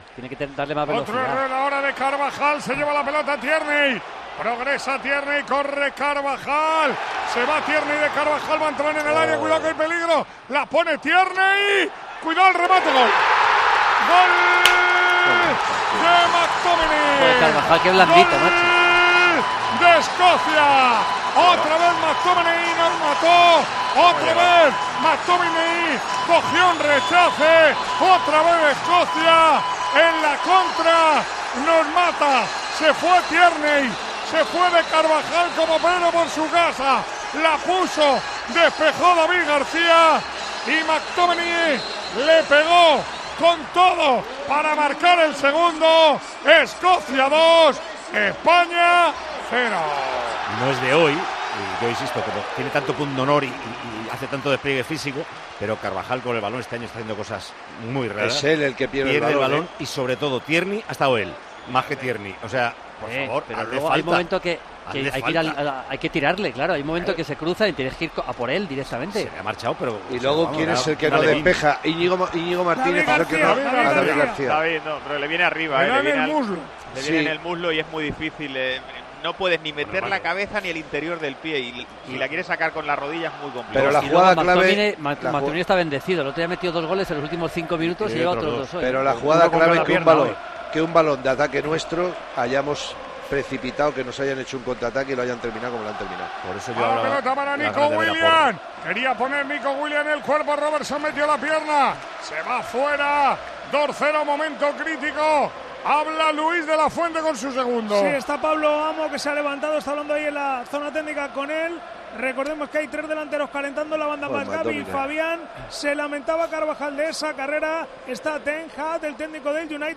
Tiempo de Juego, en Hampden Park.